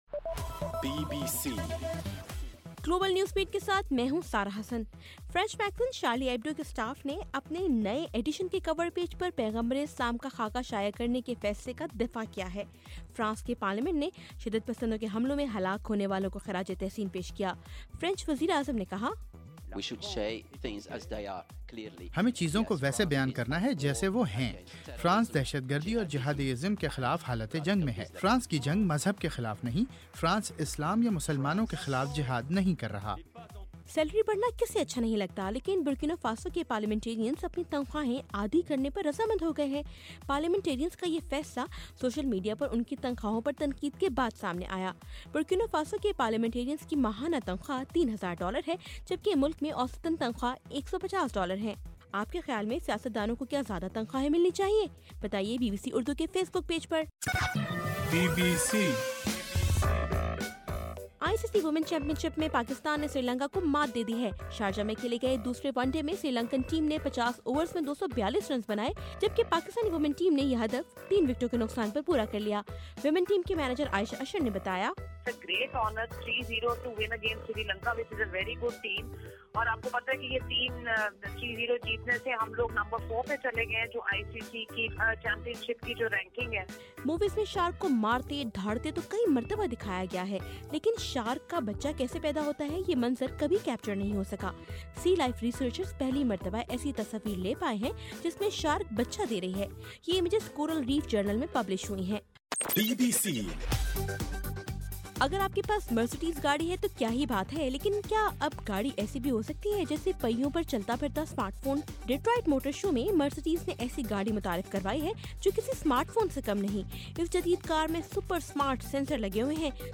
جنوری 14: صبح 1 بجے کا گلوبل نیوز بیٹ بُلیٹن